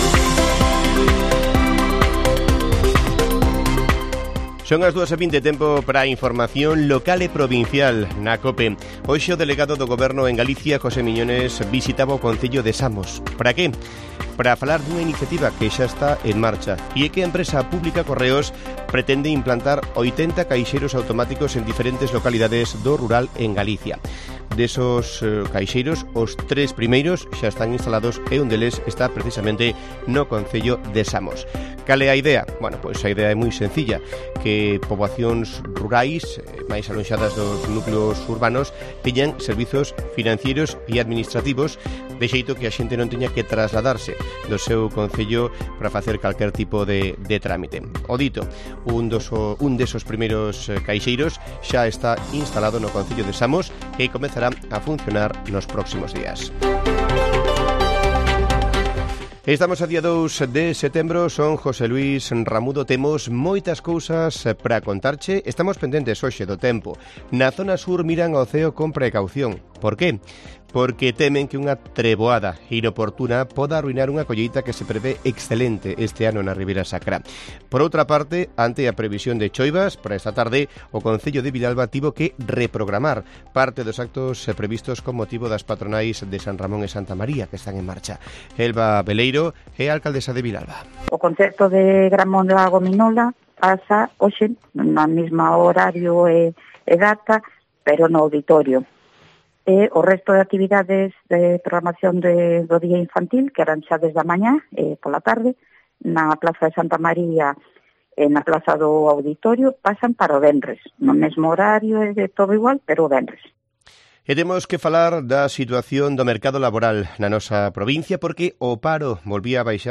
Informativo Mediodía de Cope Lugo. 02 de septiembre. 14:20 horas